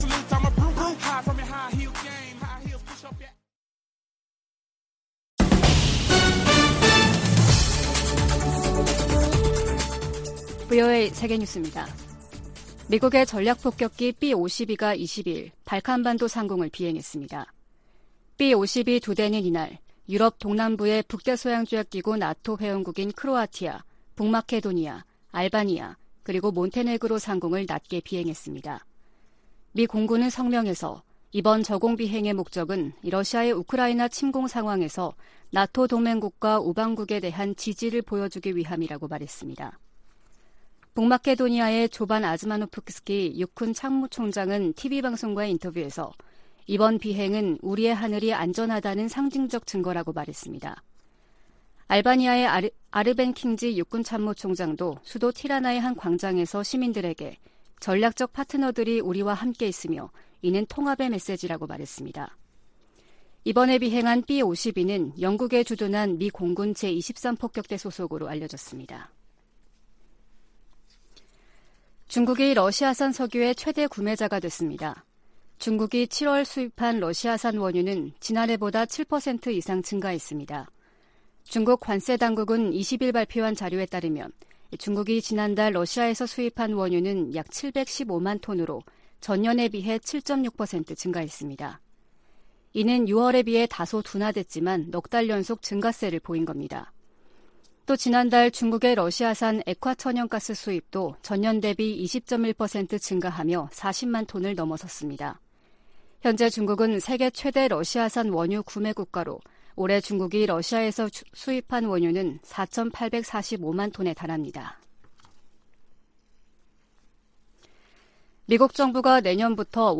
VOA 한국어 아침 뉴스 프로그램 '워싱턴 뉴스 광장' 2022년 8월 23일 방송입니다. 미군과 한국군이 을지프리덤실드(UFS) 연합훈련을 시작했습니다. 토니 블링컨 미 국무부 장관이 박진 한국 외교부 장관과의 통화에서 대일 관계 개선과 한반도 비핵화 노력에 대한 윤석열 대통령의 광복절 경축사에 감사를 표했다고 국무부가 전했습니다.